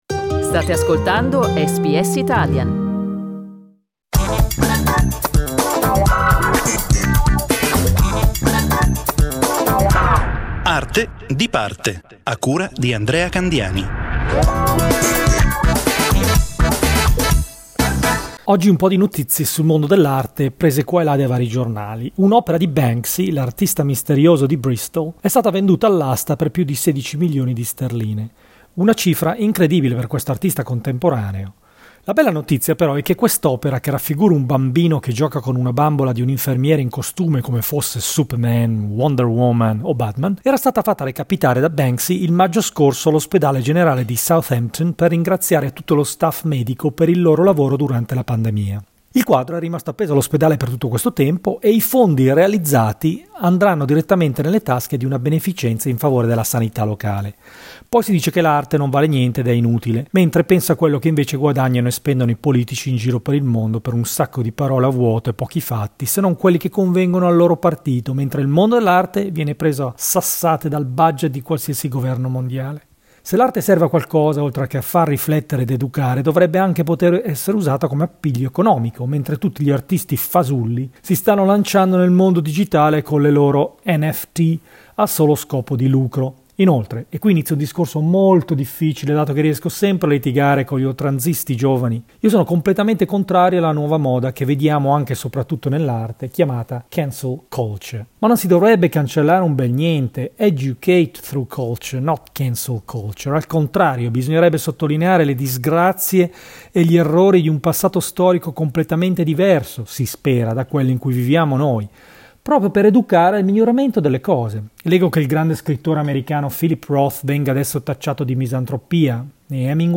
esperto di arte contemporanea